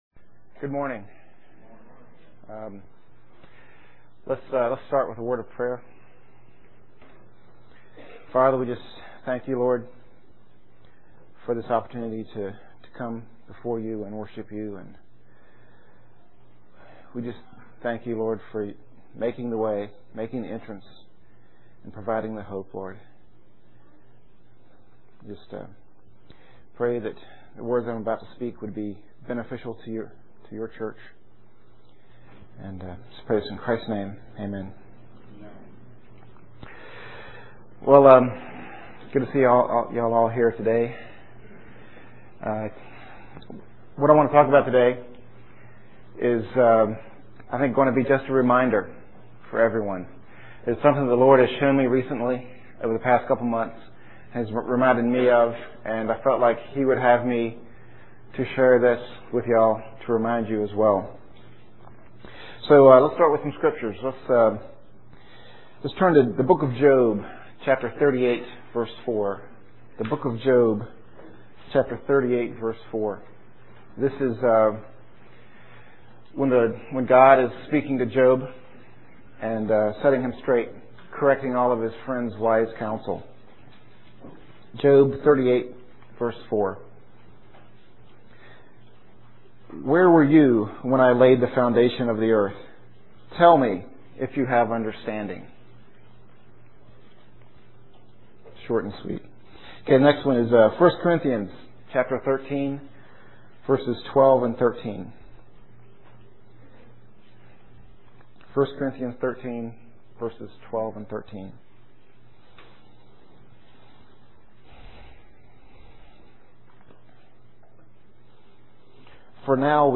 US Stream or download mp3 Summary A message about how the Lord is designing and building His Church, and how we fit into that design.